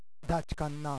能登弁とはいえ私が能登に住んでいたころ使っていた方言です。
地域によってはまったく違った言い方の場合もあります。